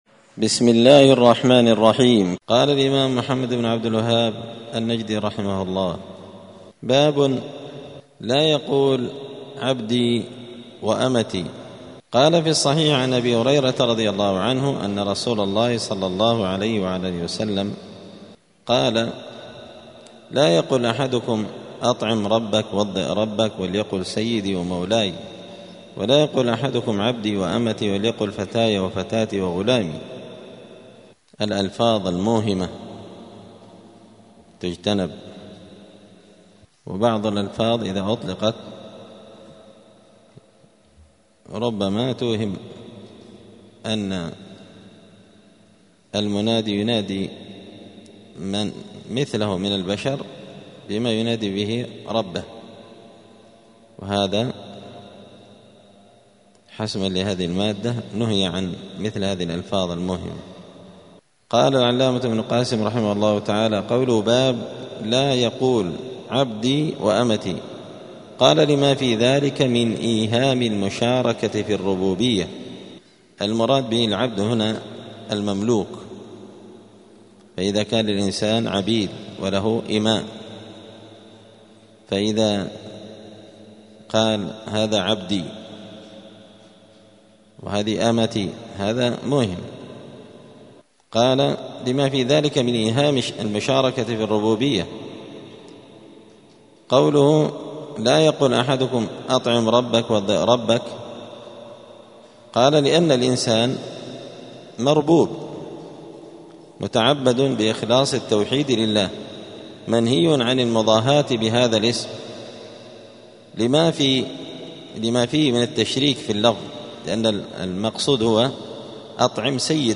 دار الحديث السلفية بمسجد الفرقان قشن المهرة اليمن
*الدرس الرابع والثلاثون بعد المائة (134) {باب لا يقول عبدي أمتي}*